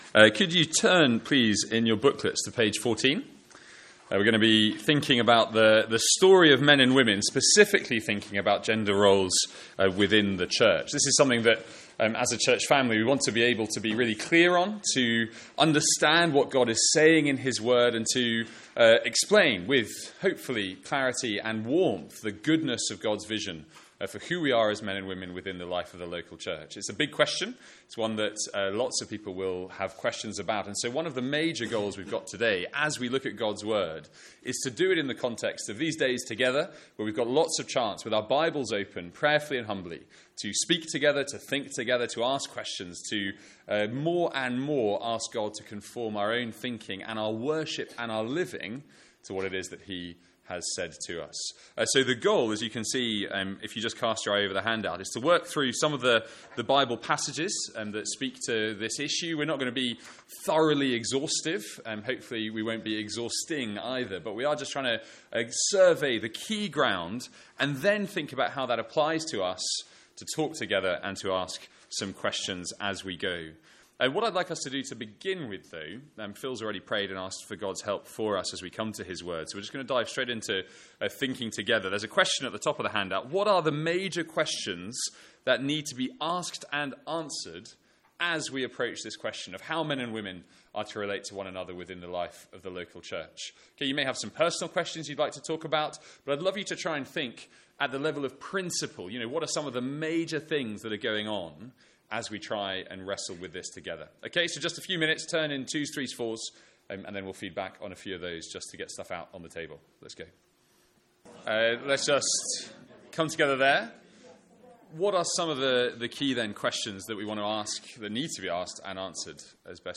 From our student Mid-Year Conference.